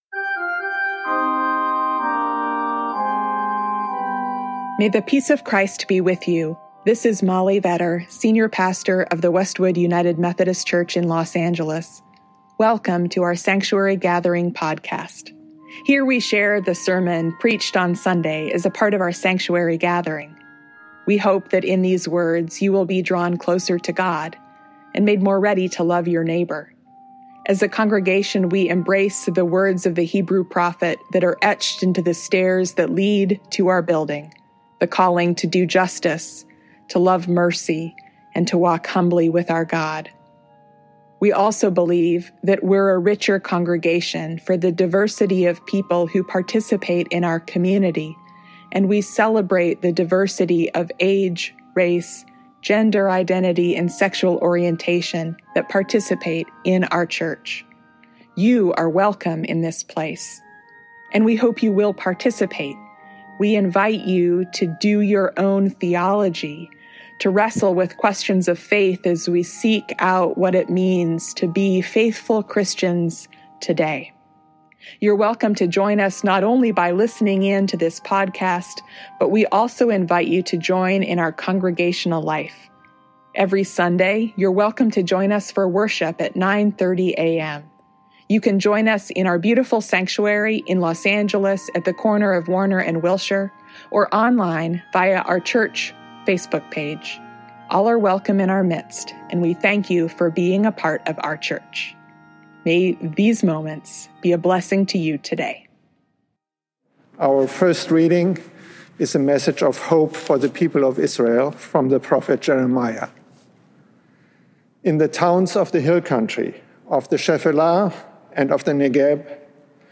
We begin the season of Advent on Sunday, with contemplative music and inspiring preaching. In this season, we prepare ourselves for Christ’s coming into the world; this is not merely historical, but an earnest prayer for Christ’s presence among us, here, now.